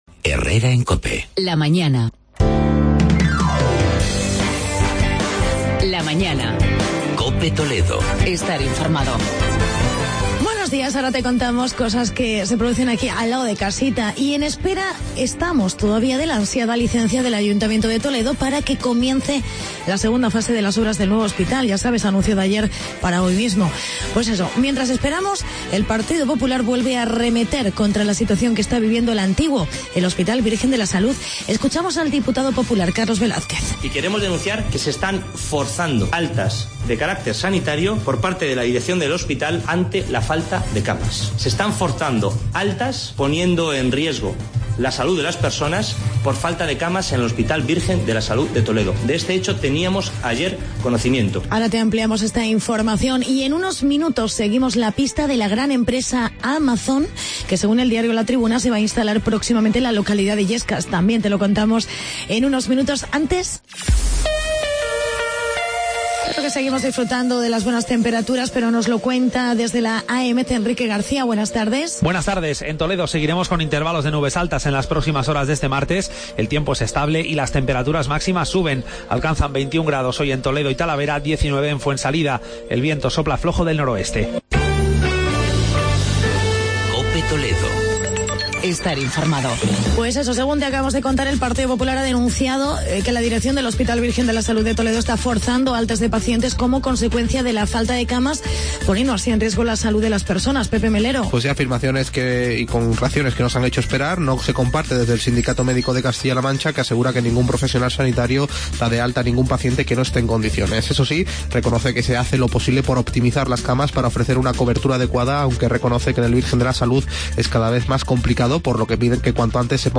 Actualidad y entrevista con José Manuel Tofiño, alcalde de Illescas.